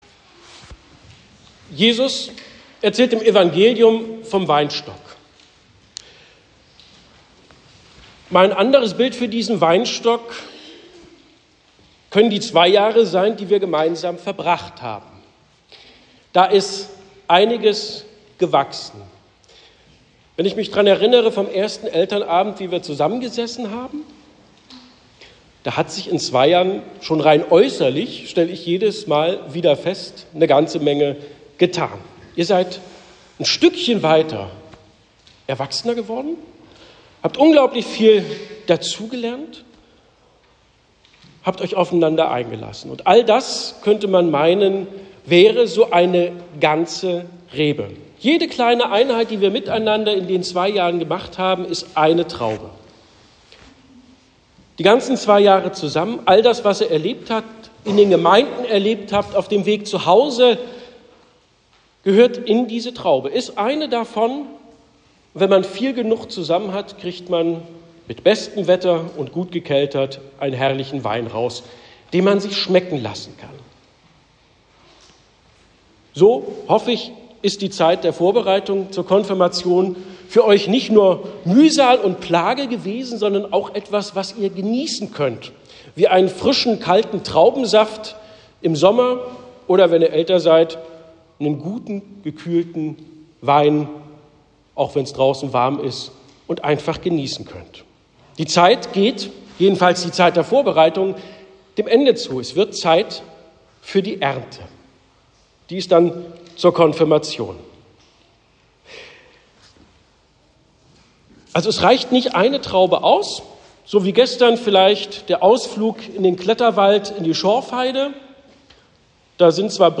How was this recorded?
Predigt zum Konfi-Vorstellungsgottesdienst